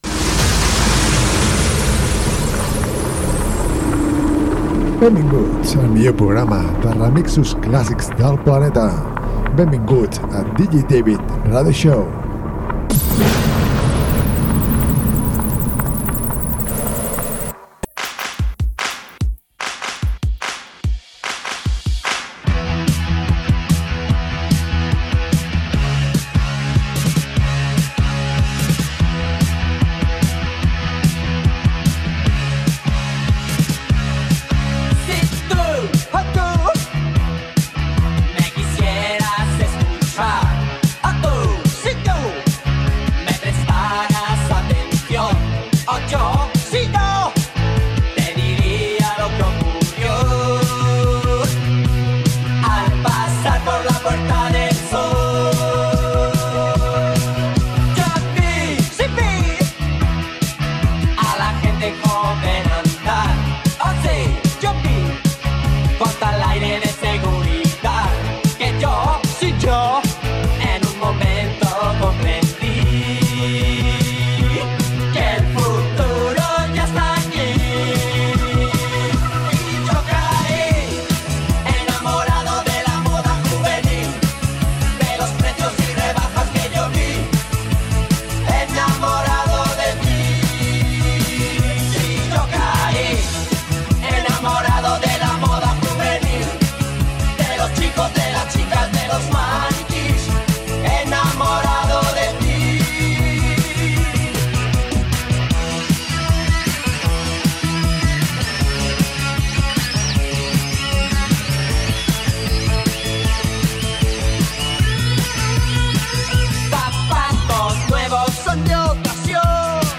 remixos classics